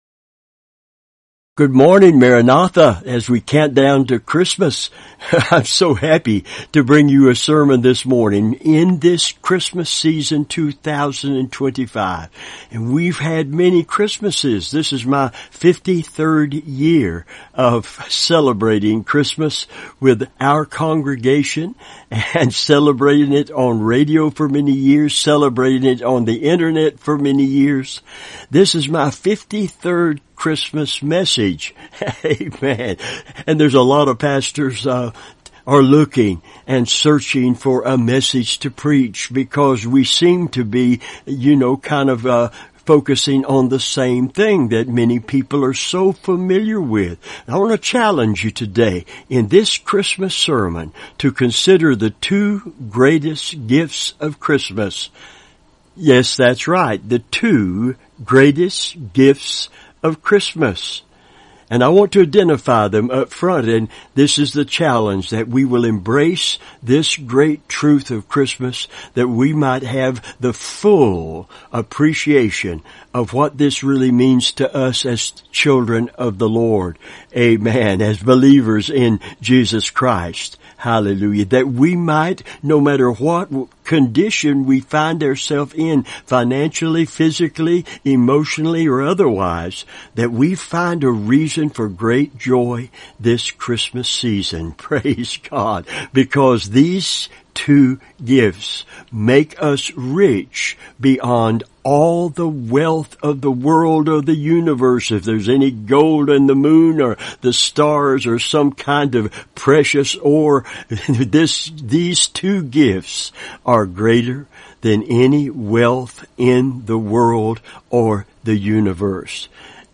Revival Sermons